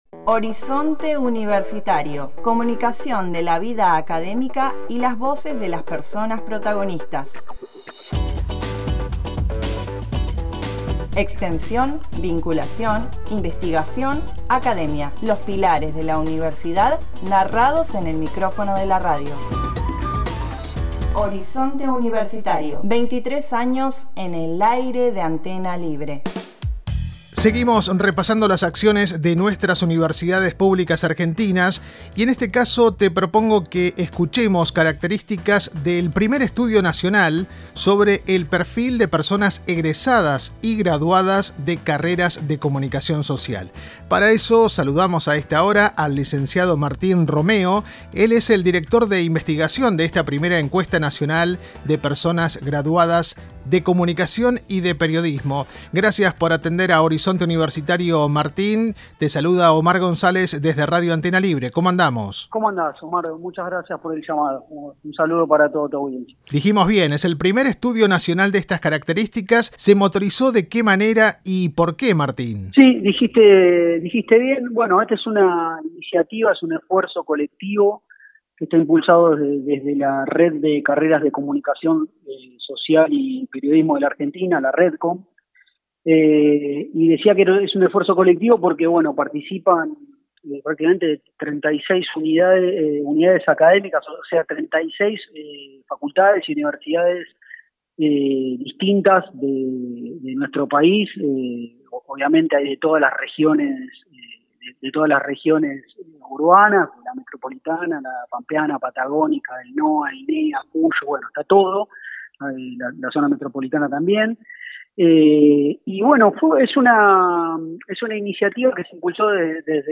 En Horizonte Universitario dialogamos